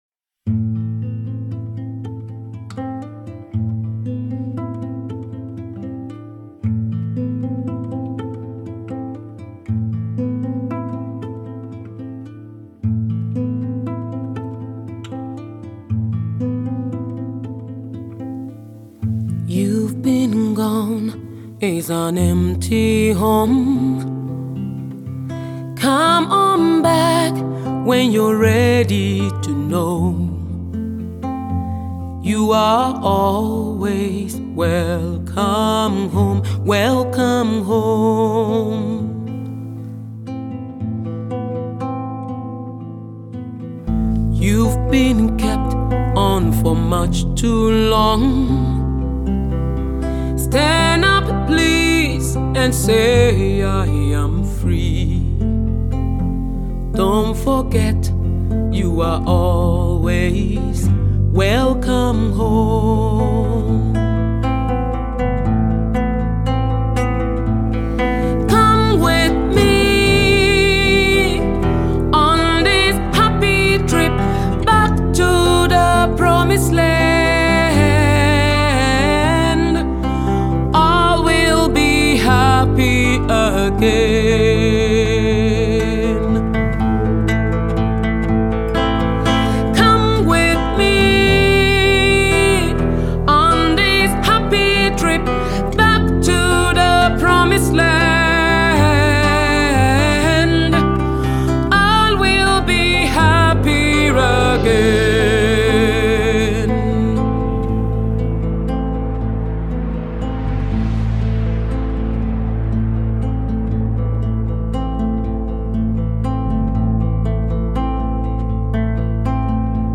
Afro-pop icon
soul-stirring ballad version
recorded live in Ljubljana